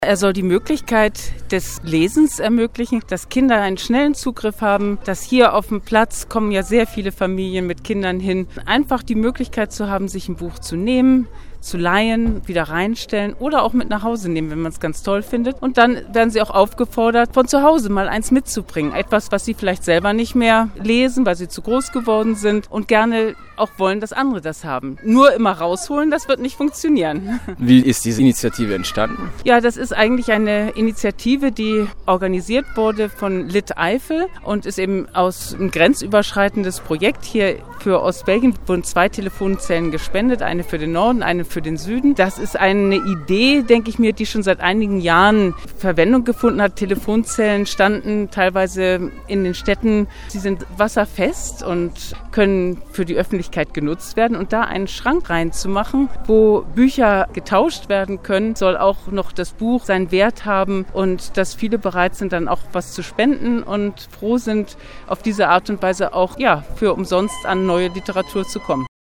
auf dem Spielplatz